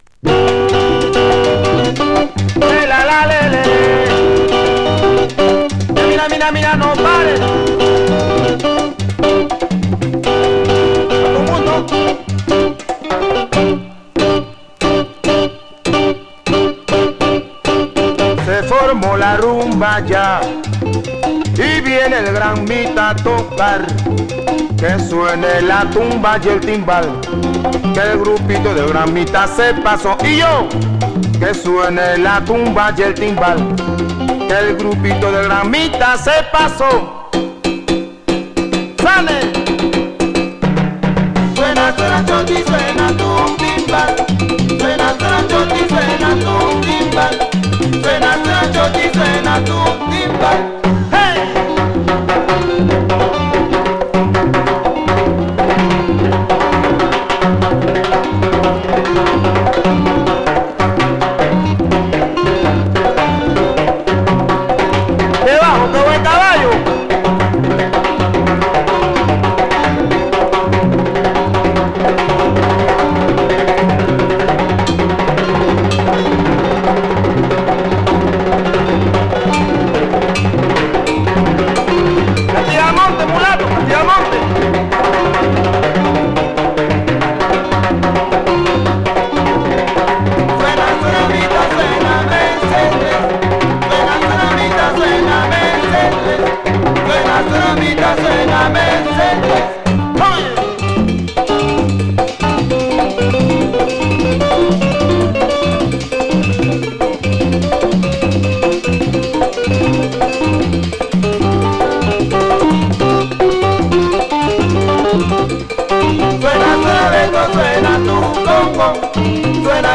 very gritty hard-edged sounds from South America